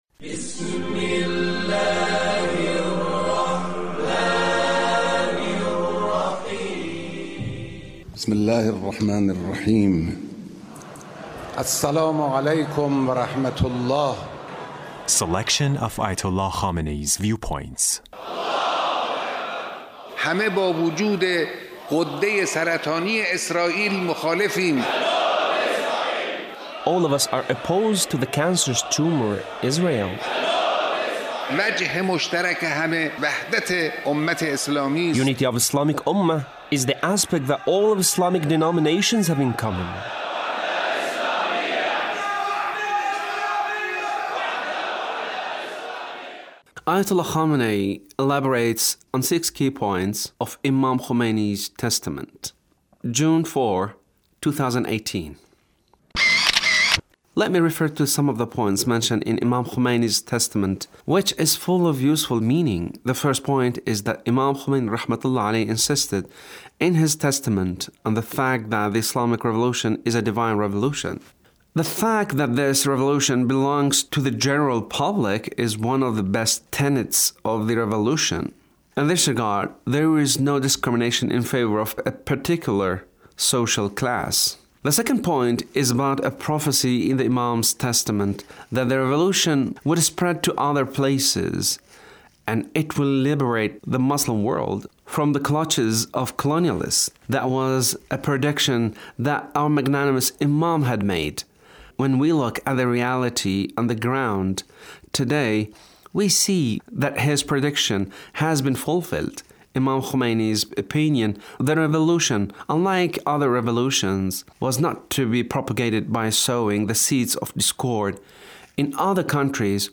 The Leader's speech on Quds Day